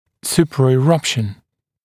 [ˌsuprəɪ’rʌpʃn][ˌсупрэи’рапшн]супрапрорезывание, прорезывание выше уровня рядом стоящих зубов (зубного ряда)